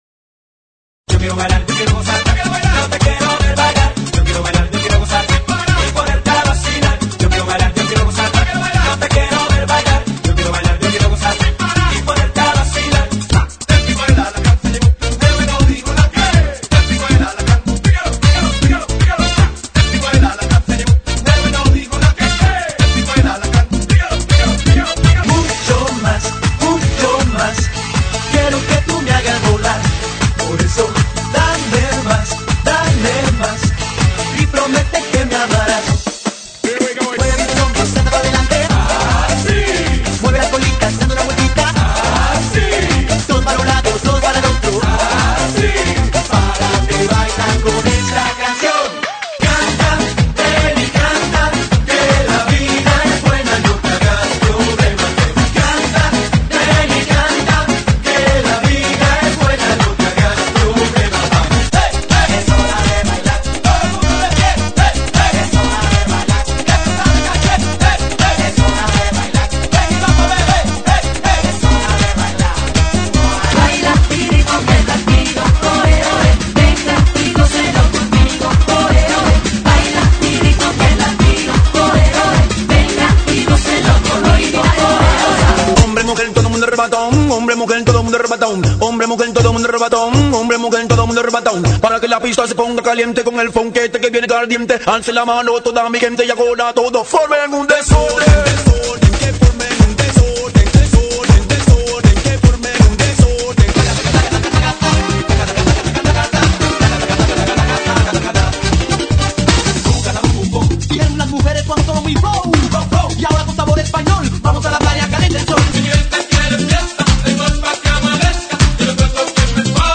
GENERO: LATINO